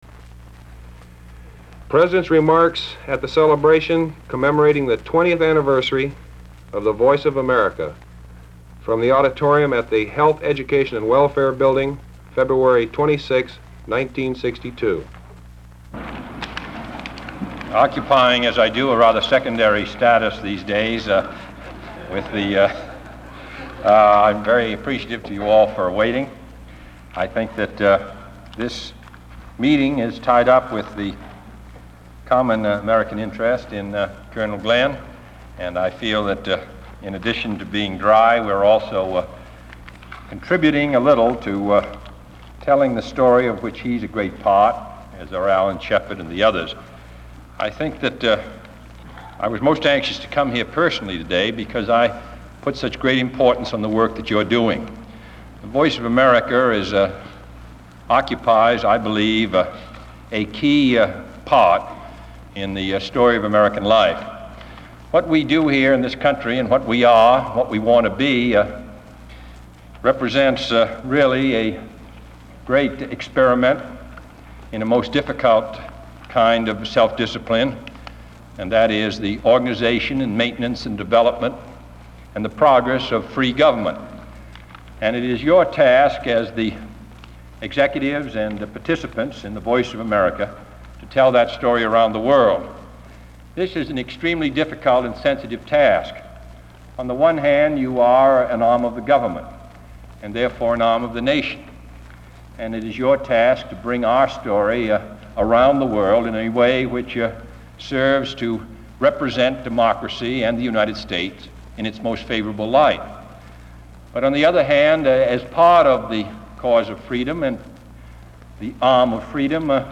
Sound recording of President John F. Kennedy’s remarks at a celebration commemorating the 20th anniversary of the Voice of America held in the Health, Education, and Welfare Auditorium.